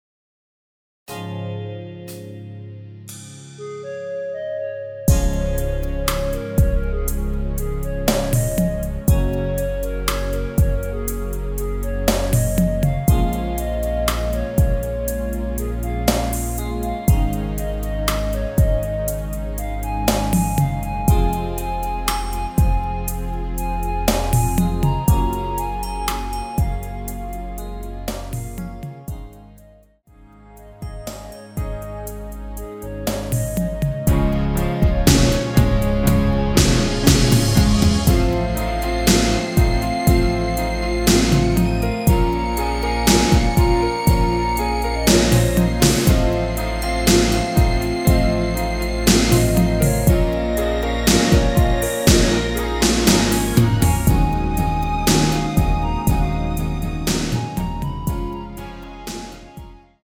전주 없이 시작 하는곡이라 전주 1마디 만들어 놓았습니다.(미리듣기 확인)
원키 멜로디 포함된 MR입니다.(미리듣기 확인)
Db
미리듣기는 저작권법상 최고 1분 까지라서
앞부분30초, 뒷부분30초씩 편집해서 올려 드리고 있습니다.
(멜로디 MR)은 가이드 멜로디가 포함된 MR 입니다.